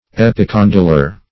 Search Result for " epicondylar" : The Collaborative International Dictionary of English v.0.48: Epicondylar \Ep`i*con"dy*lar\, n. (Anat.)
epicondylar.mp3